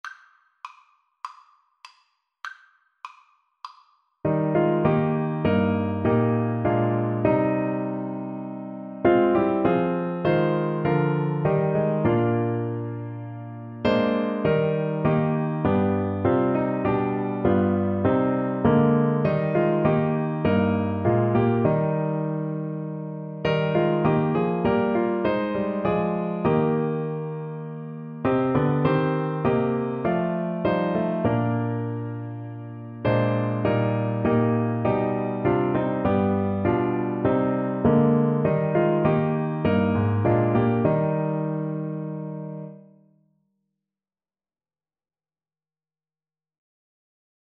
4/4 (View more 4/4 Music)
Classical (View more Classical Saxophone Music)